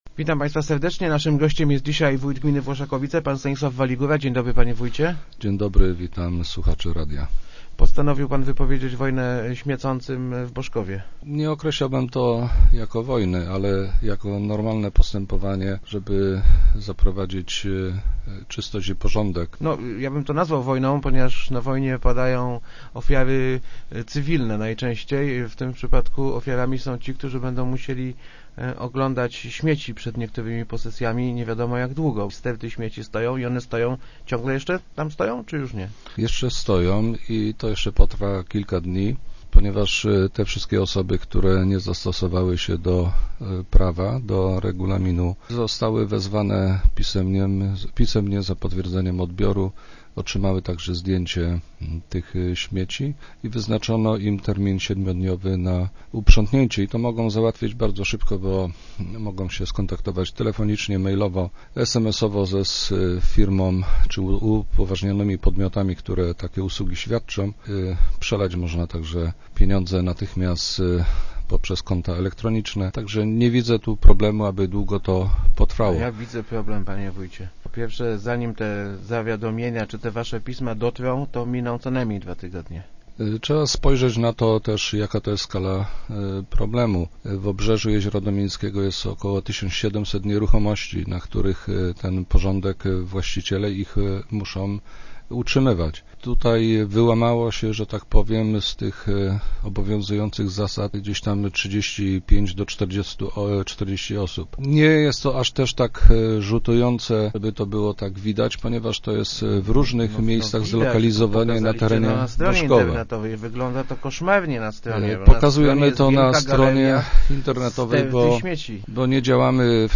Nie posprzątamy ich sami – zapewnił w Rozmowie Elki wójt Włoszakowic Stanisław Waligóra – czekamy aż zrobią to ci, którzy zrobili bałagan. Wójt przypomina, że w gminie funkcjonuje regulamin, który premiuje tych, którzy segregują śmieci.